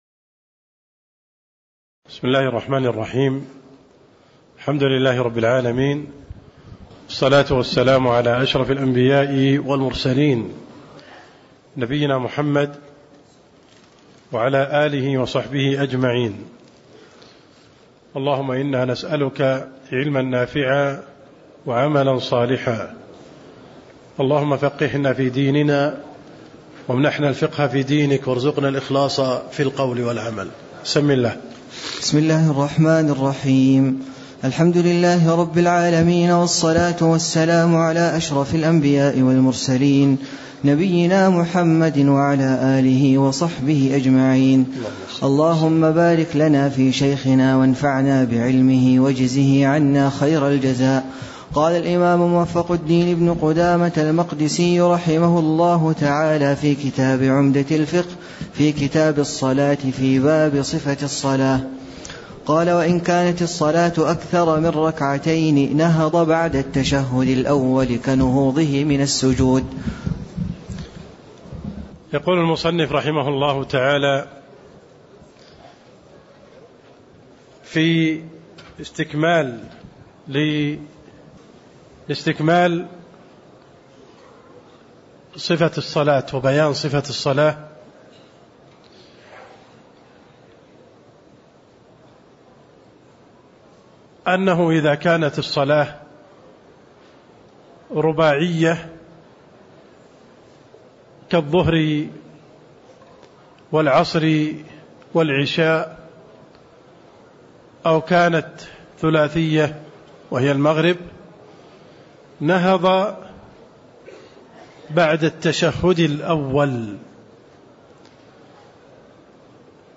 تاريخ النشر ٢٤ صفر ١٤٣٦ هـ المكان: المسجد النبوي الشيخ: عبدالرحمن السند عبدالرحمن السند باب صفة الصلاة (09)